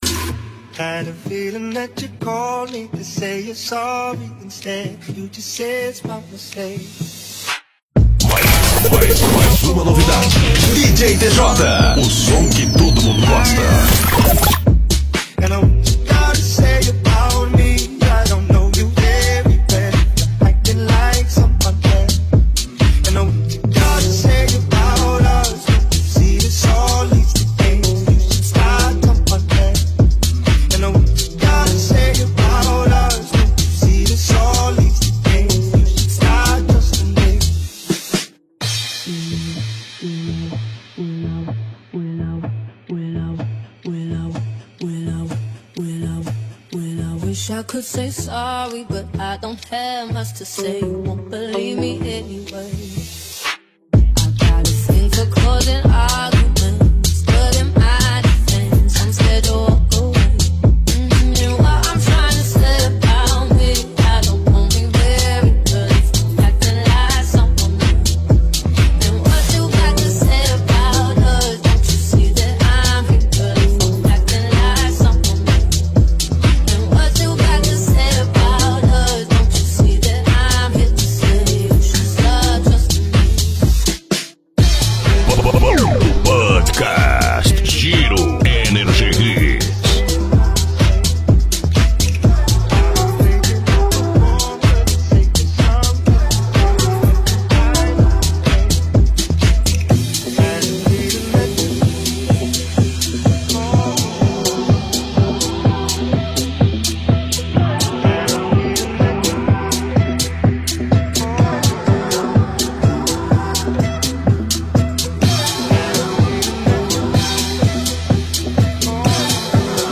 Deep-House Electro-House